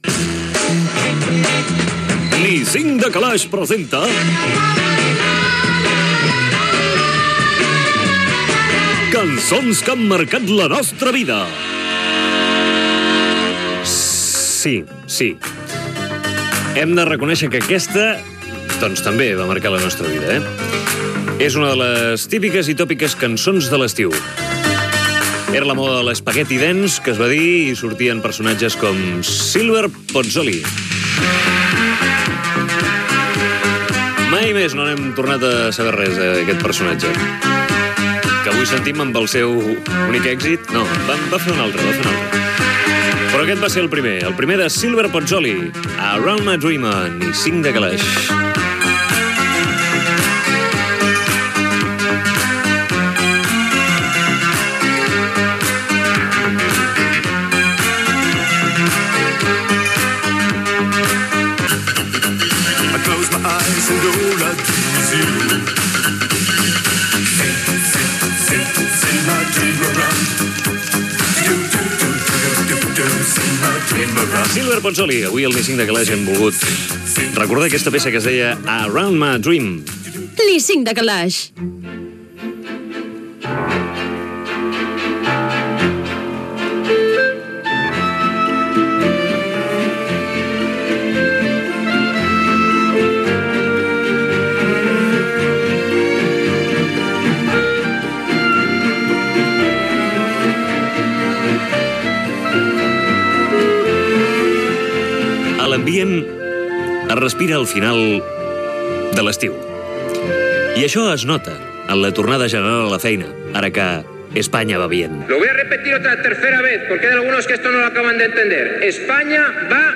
Espai "Cançons que han marcat la nostra vida", indicatiu del programa, comentari sobre l'estiu, indicatiu, equip, sorteig a l'endemà, presentació del concursant
Entreteniment
FM